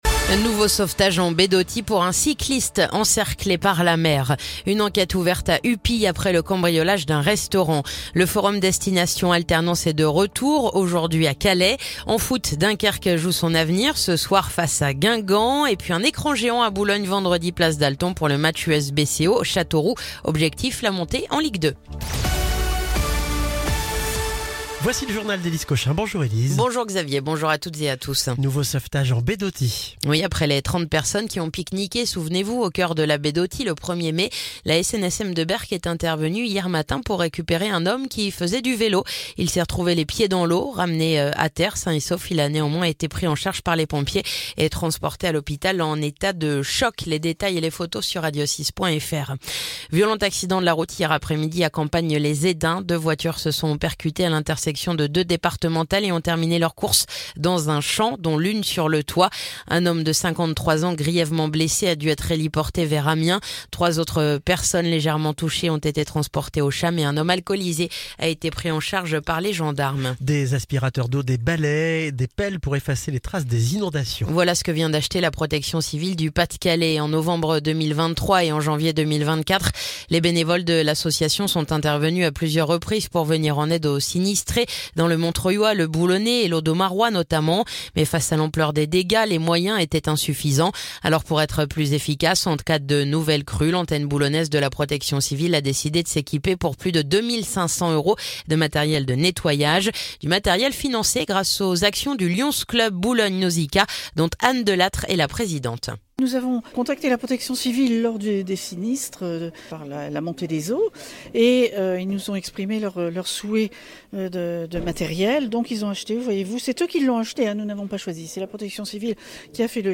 Le journal du mercredi 14 mai